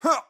MaleGrunt.wav